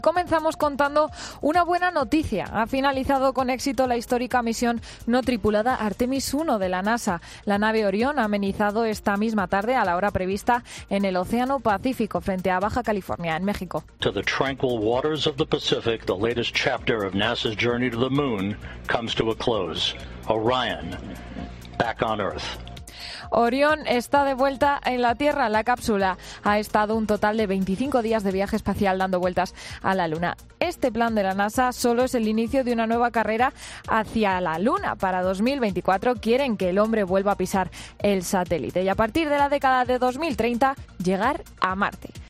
La histórica misión Artemis I concluye con éxito, amplía la noticia el equipo de Informativos COPE